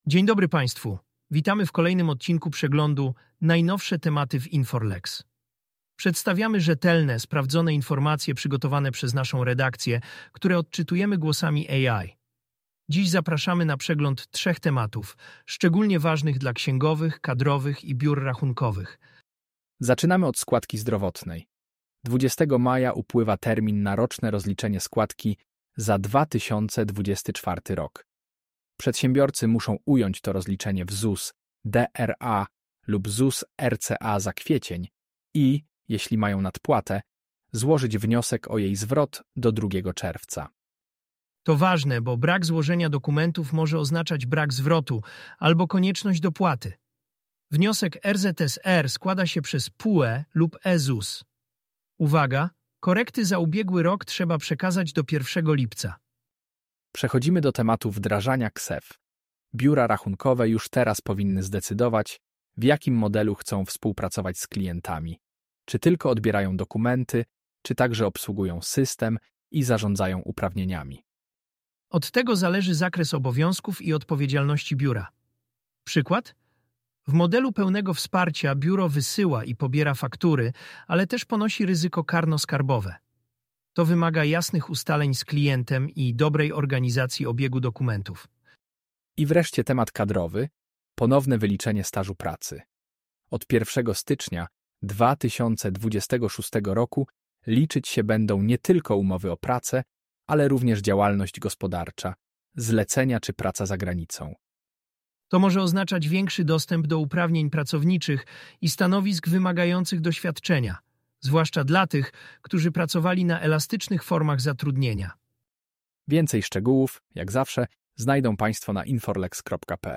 Głosy AI przedstawią najważniejsze tematy opracowane przez naszych ekspertów – w innowacyjnym formacie audio.